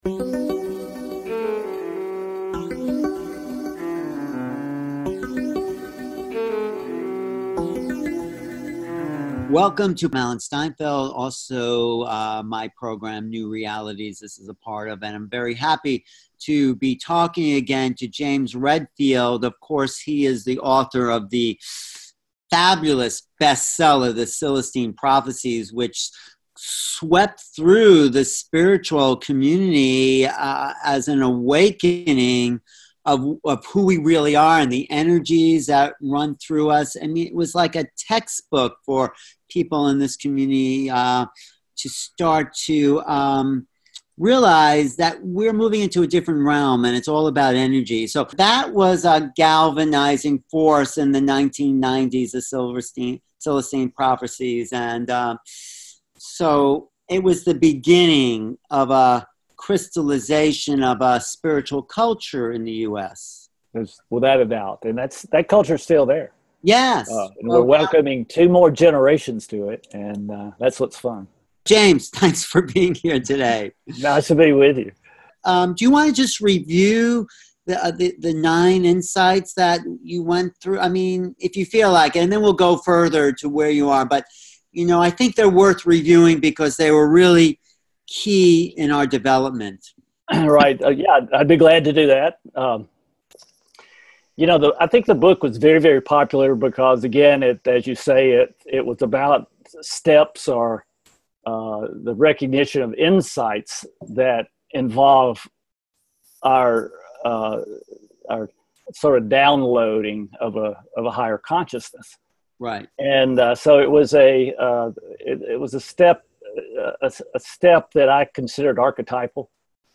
Guest, James Redfield Celestine, author of the Celestine Prophecies Revisited